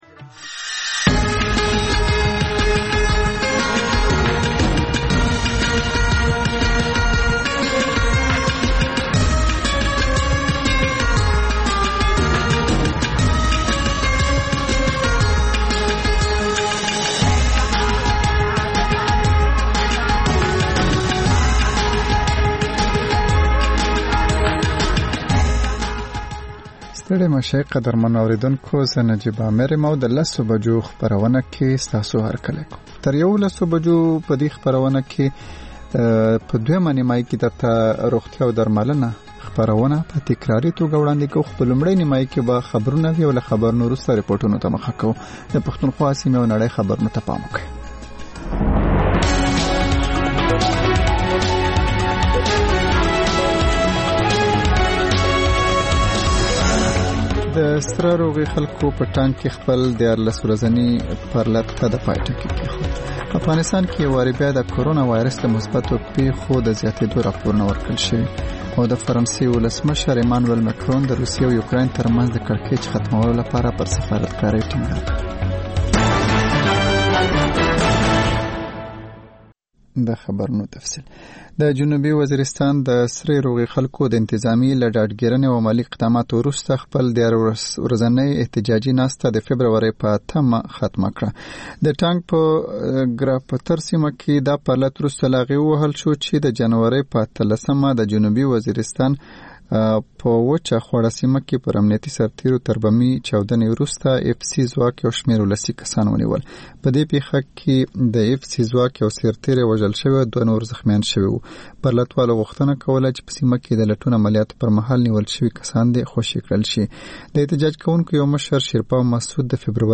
په دې خپرونه کې تر خبرونو وروسته بېلا بېل رپورټونه، شننې او تبصرې اورېدای شﺉ. د خپرونې په وروستیو پینځلسو دقیقو یا منټو کې یوه ځانګړې خپرونه خپرېږي.